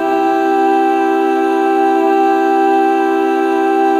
chorus.wav